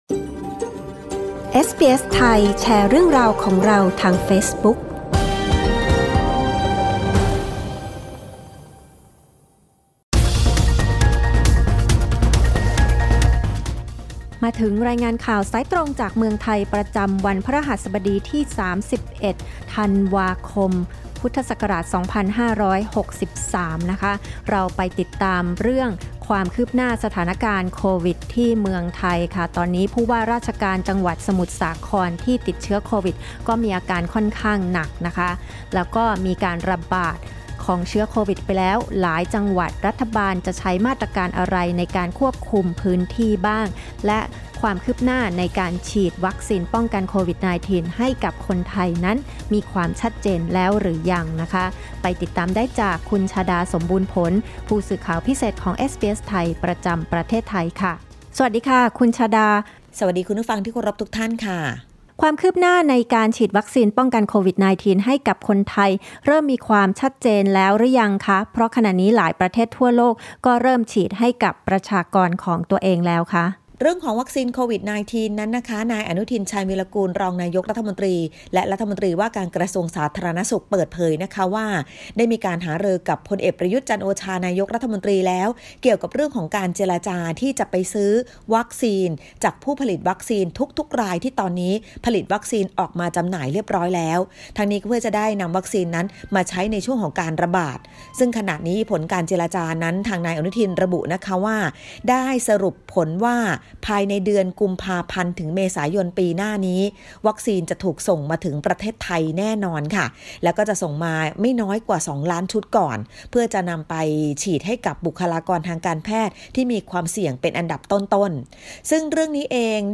รายงานข่าวสายตรงจากเมืองไทย